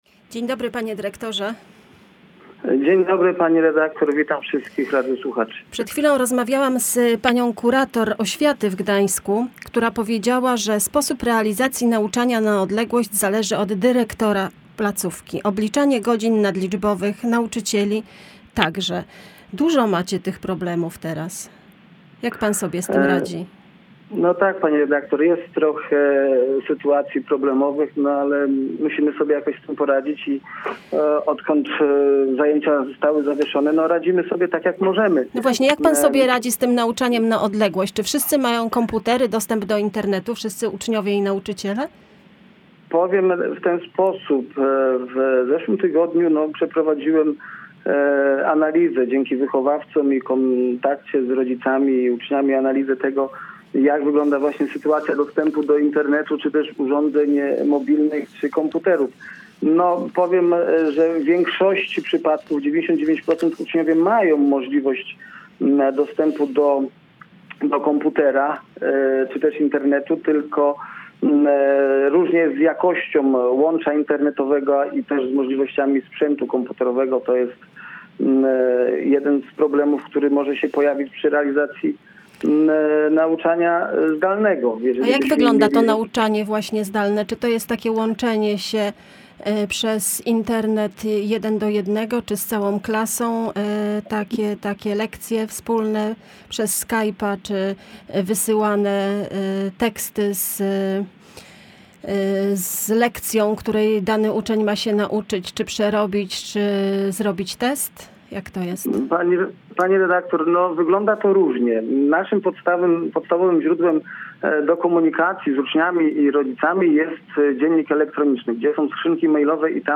Posłucha całej rozmowy: /audio/dok3/dyrszkolrozm230320.mp3 Tagi: COVID-19 edukacja nauczanie zdalne szkoła